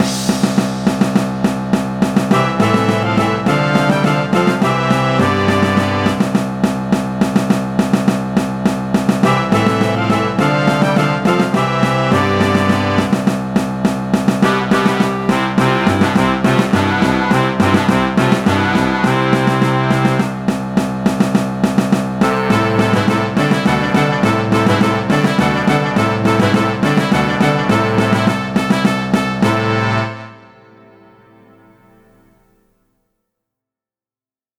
(In orchestration. No video.)